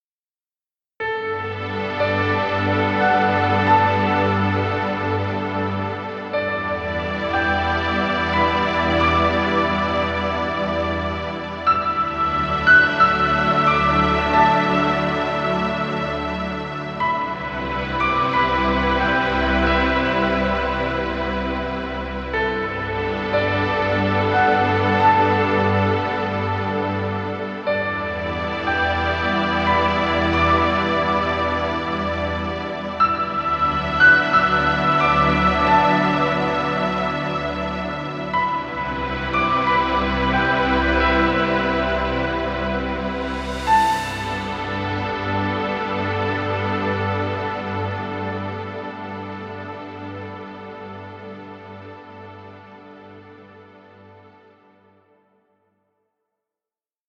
Royalty Free Music.